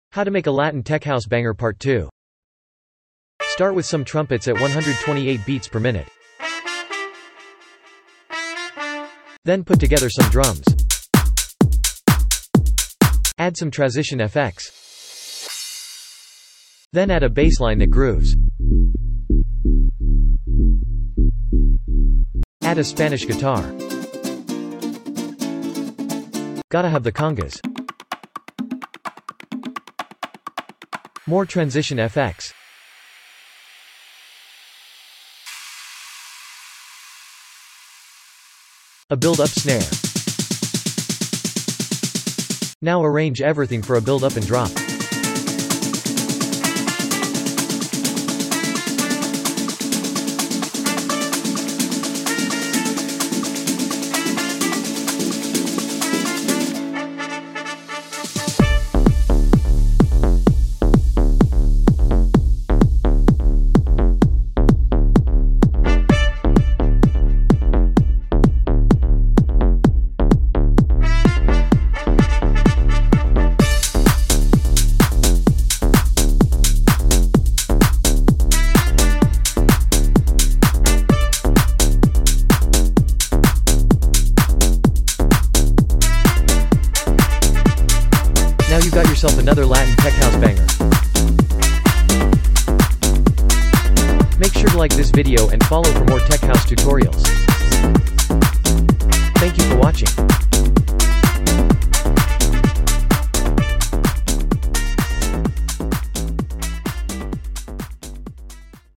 Latin Tech house tutorial